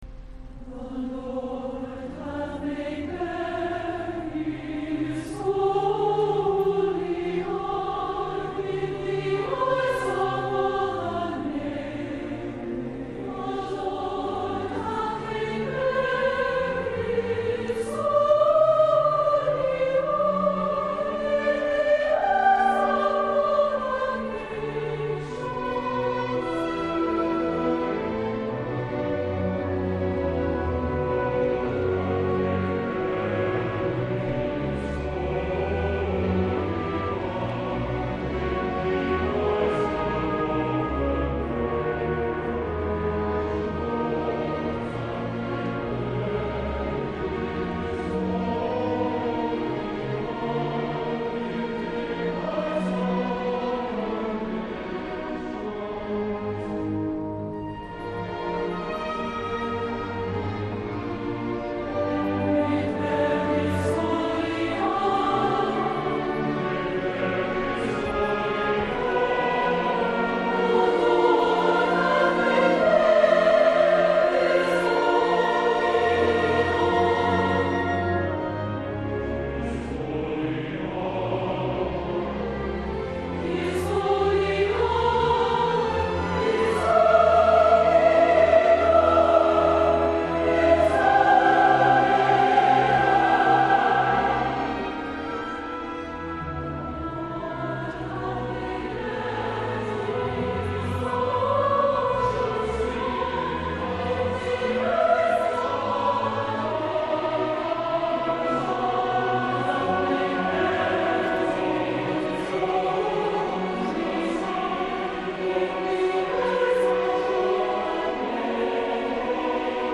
Leroy J. Robertson:  Oratorio from the Book of Mormon